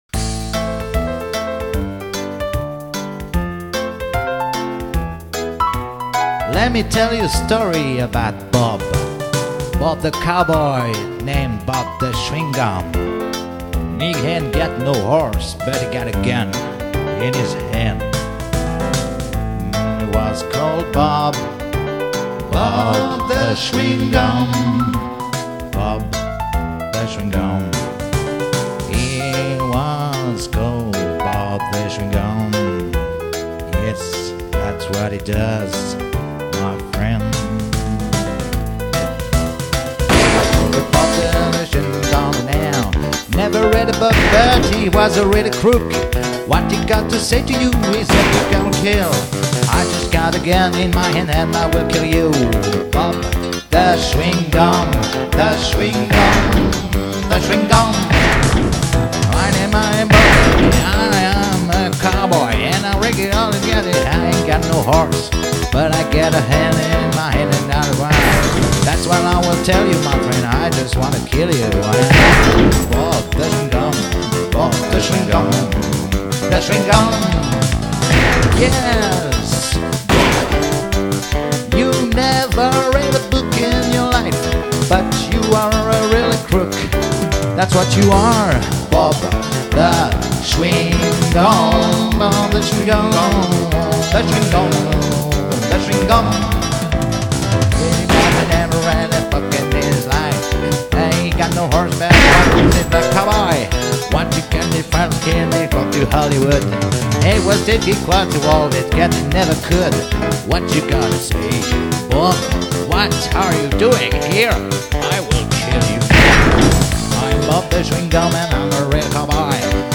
Country à la cow-boy. 2004